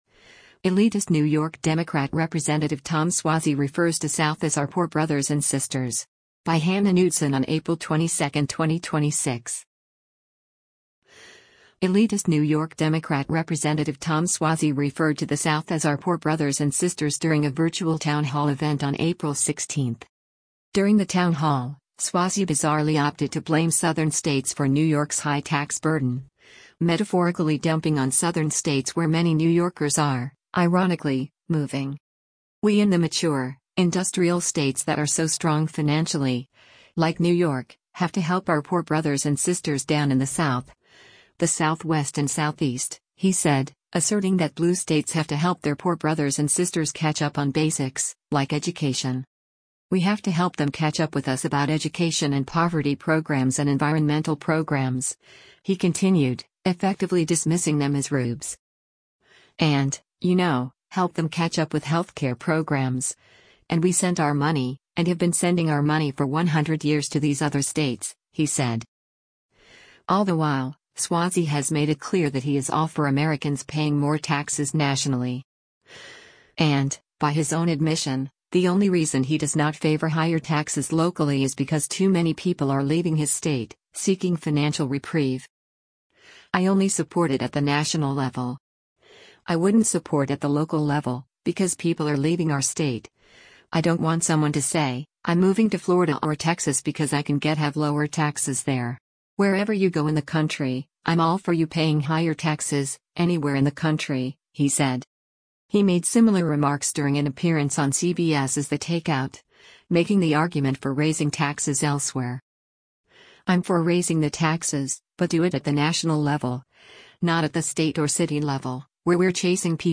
Elitist New York Democrat Rep. Tom Suozzi referred to the south as “our poor brothers and sisters” during a virtual town hall event on April 16.